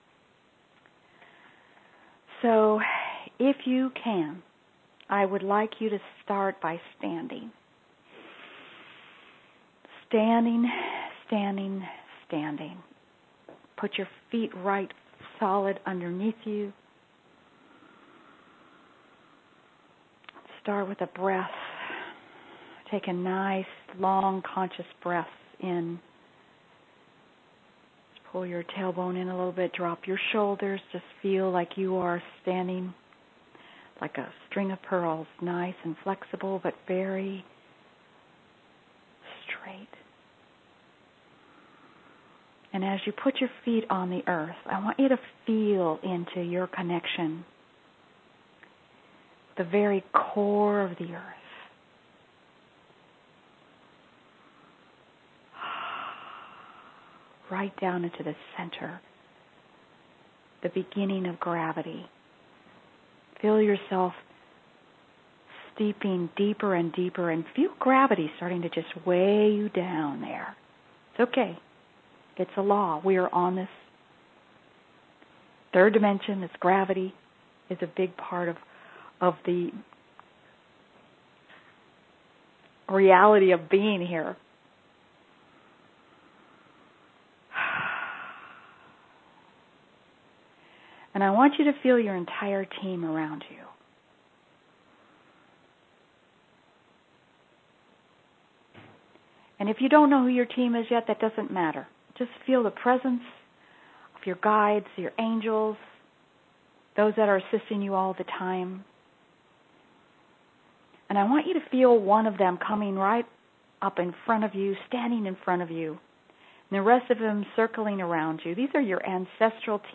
Heart’s Desire Guided Meditation